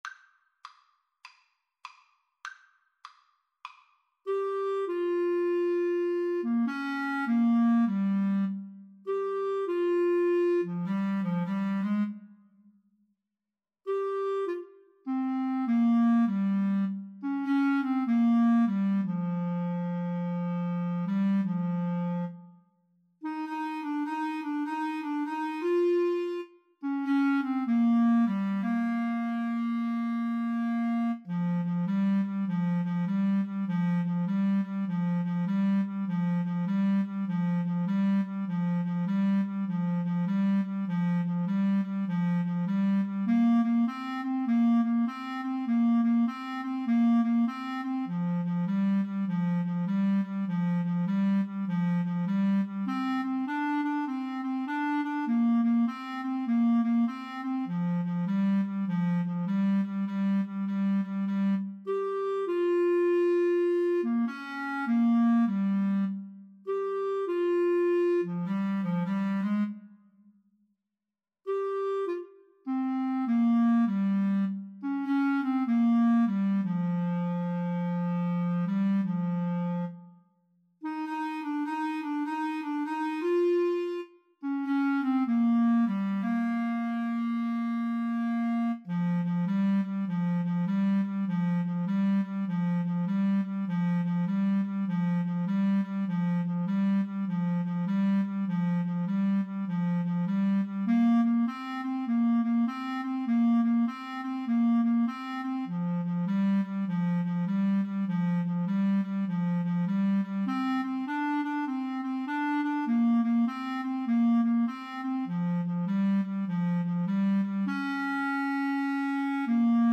Bb major (Sounding Pitch) C major (Clarinet in Bb) (View more Bb major Music for Clarinet Duet )
4/4 (View more 4/4 Music)
Moderato
Clarinet Duet  (View more Easy Clarinet Duet Music)